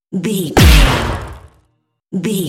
Dramatic hit electricity
Sound Effects
Atonal
heavy
intense
dark
aggressive
hits